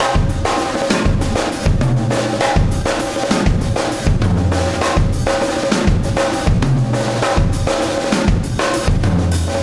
Extra Terrestrial Beat 24.wav